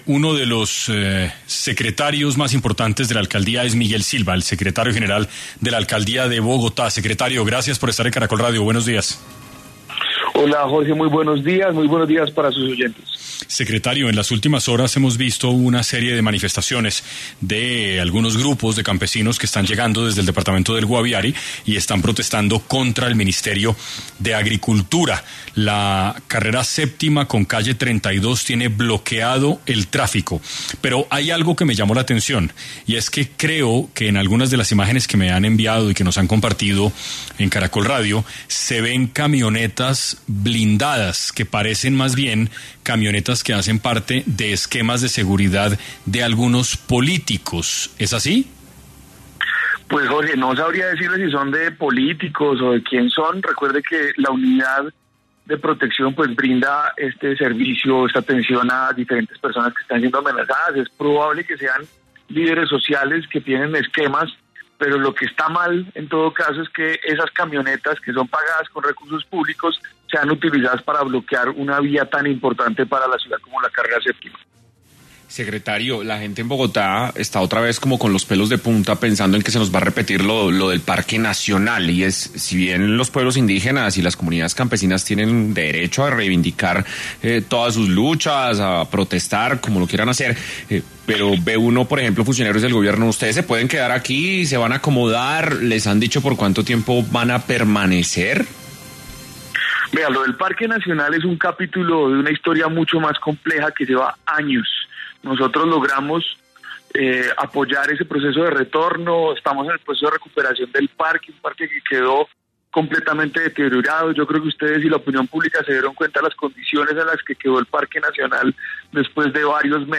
En Caracol Radio estuvo Miguel Silva, secretario general de la Alcaldía de Bogotá